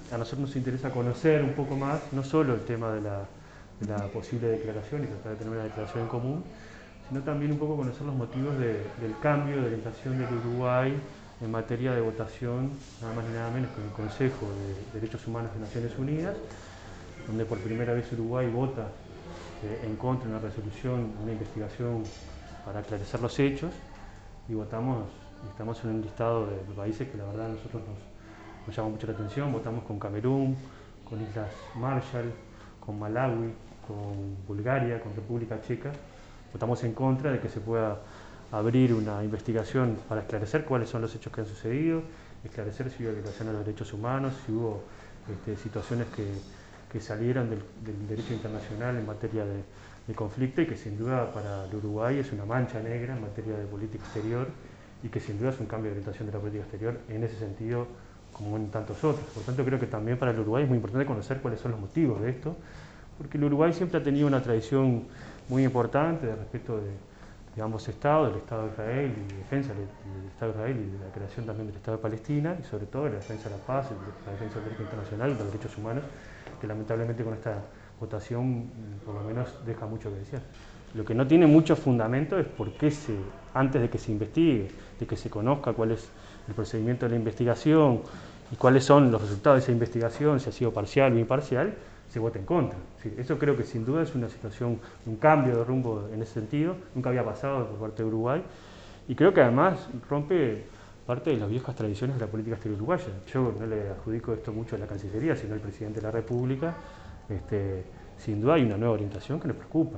«Para el Uruguay es una mancha negra en materia de política exterior y sin dudas es un cambio de orientación», dijo el representante este viernes en conferencia de prensa.